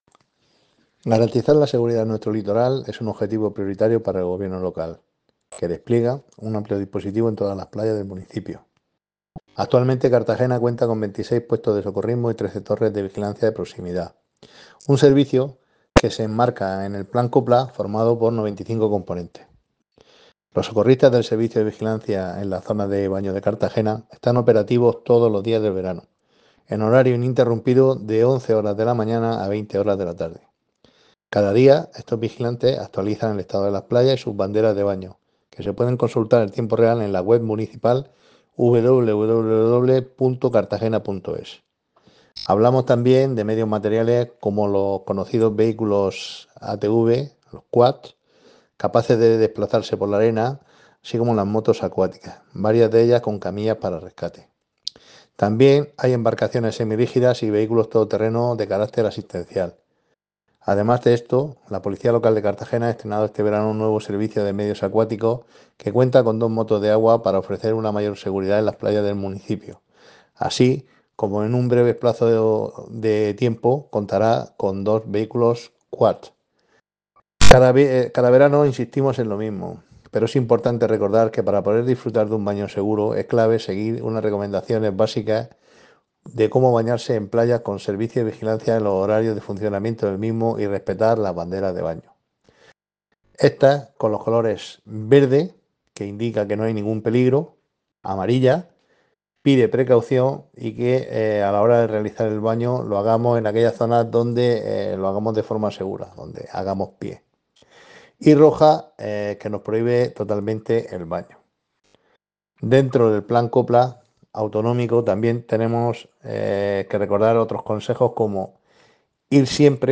Enlace a Declaraciones del concejal de Seguridad Ciudadana, José Ramón Llorca.